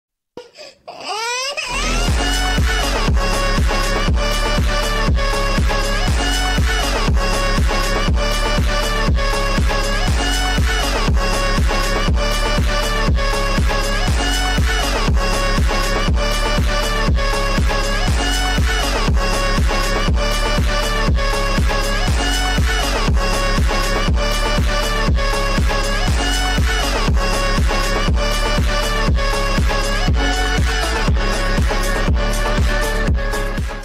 crying baby phonk.mp3